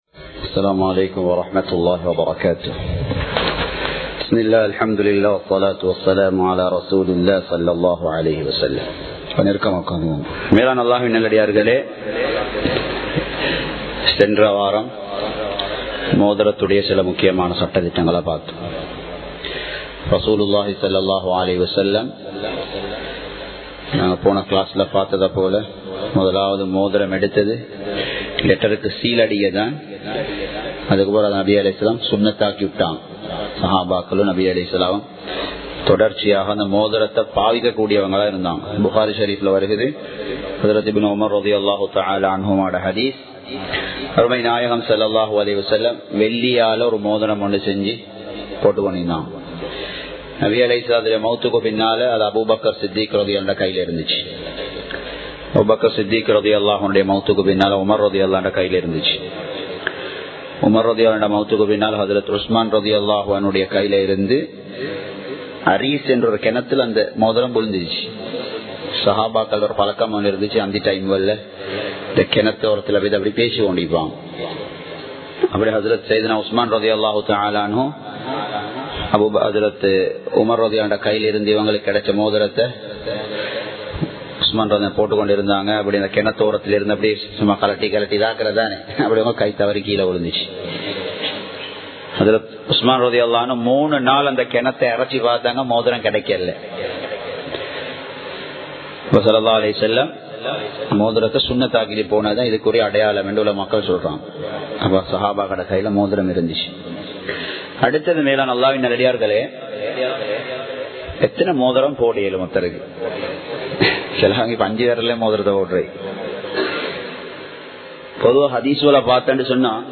Colombo 06, Mayura Place, Muhiyadeen Jumua Masjith